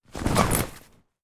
pickup.wav